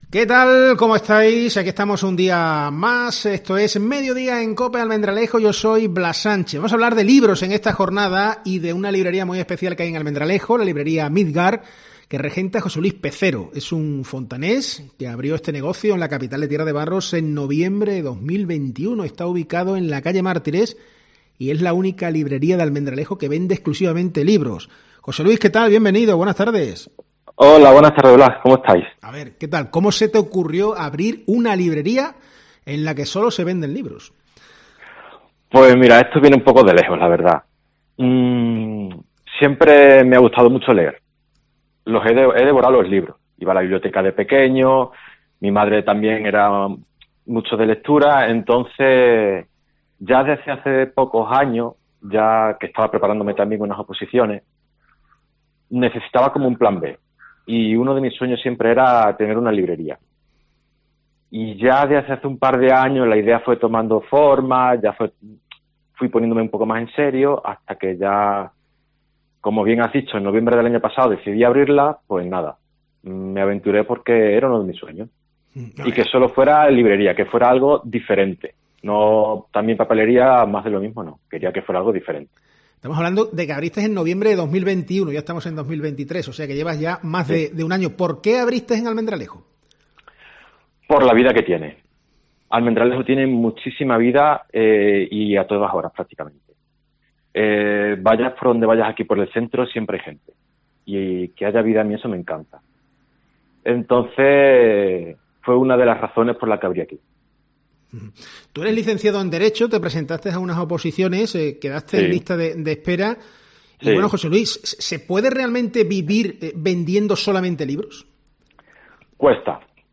En COPE, hemos entrevistado